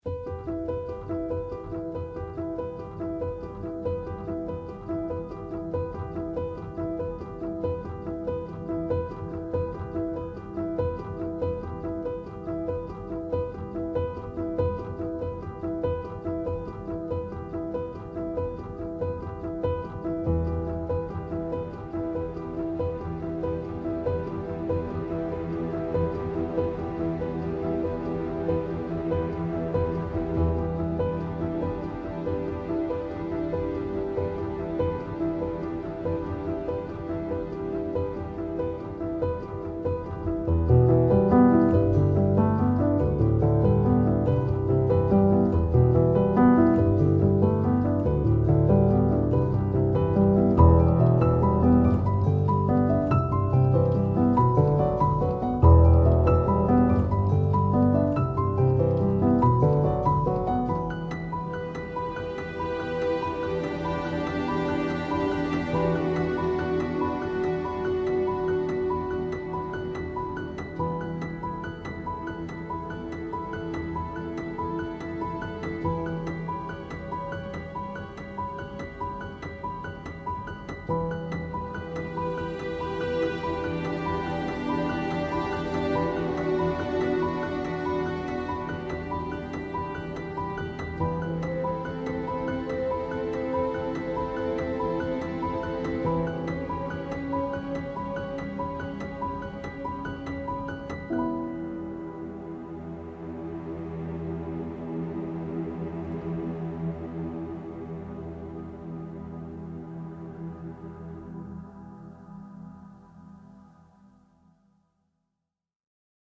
これからなにか悲劇が起きそうな雰囲気の曲
クラシカル, シネマチック 1:56 ダウンロード